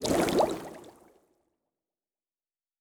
pgs/Assets/Audio/Fantasy Interface Sounds/Potion and Alchemy 14.wav at master
Potion and Alchemy 14.wav